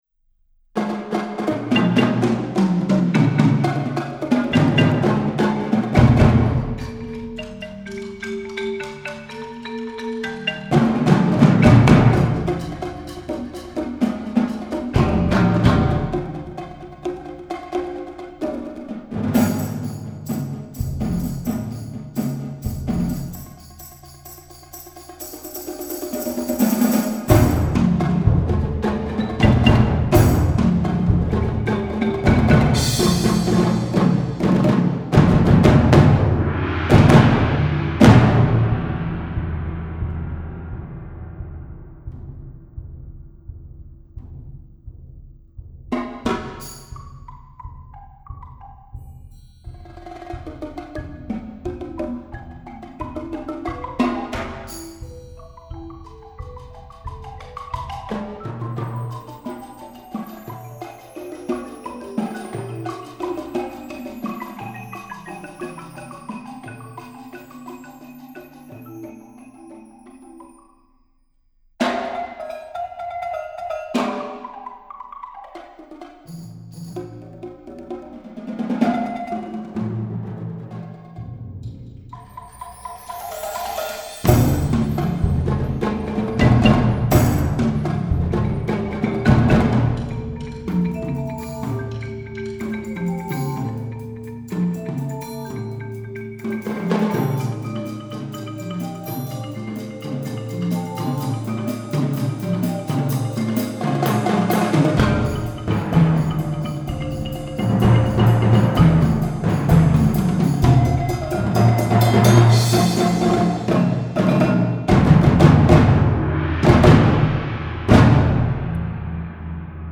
Bladmuziek voor flexibel ensemble.